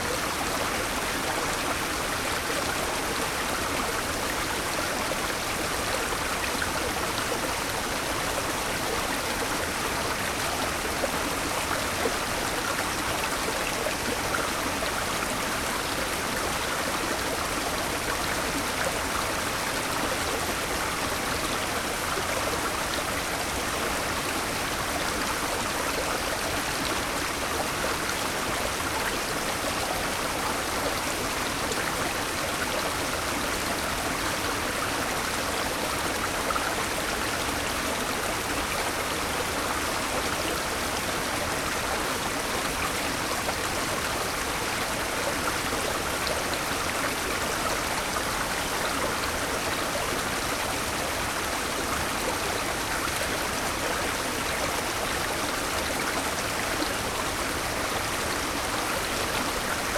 river2.ogg